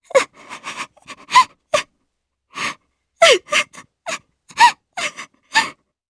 Kirze-Vox_Sad_jp.wav